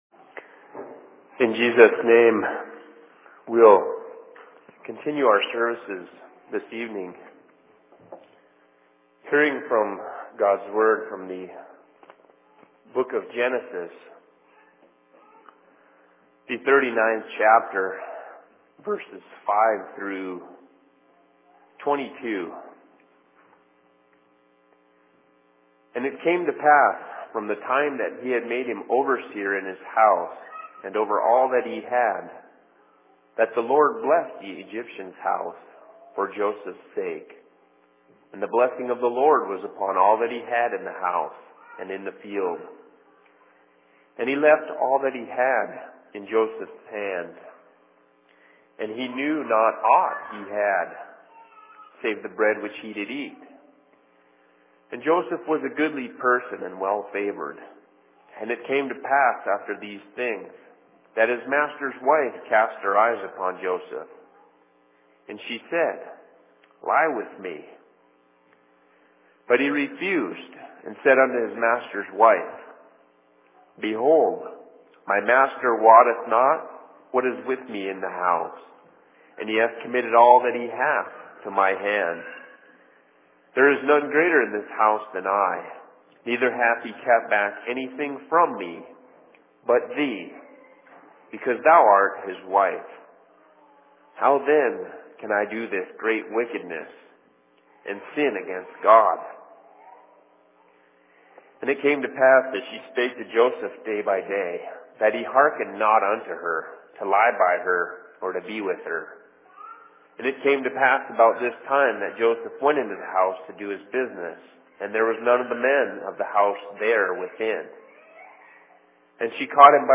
Sermon in Seattle 13.02.2011
Location: LLC Seattle